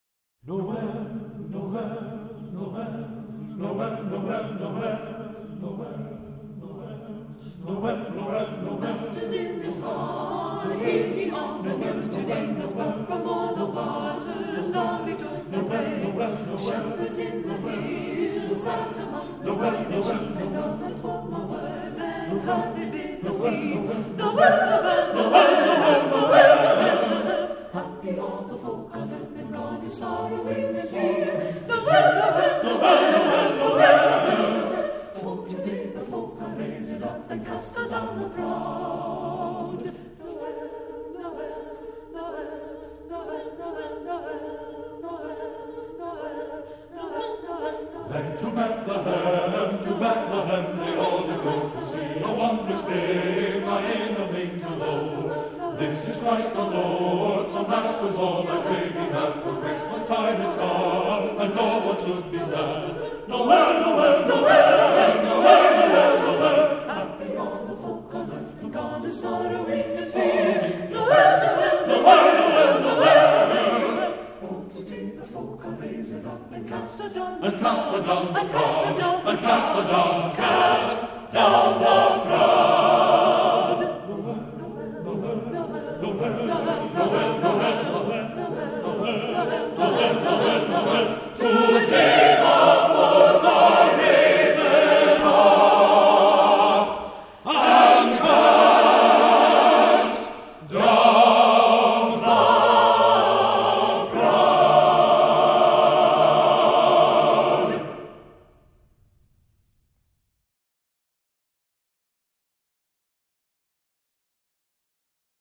Renaissance Singers
yule tide Old English cannons